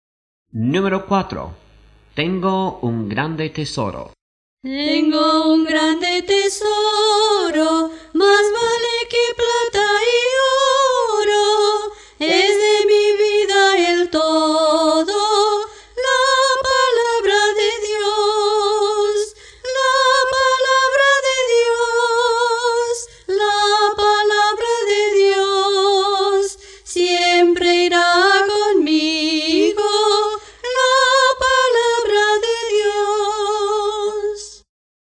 Las melodías usadas corresponden a la música original.